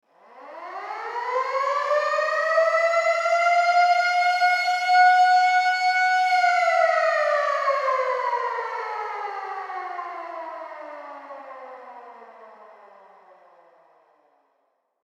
Air Raid Siren Sound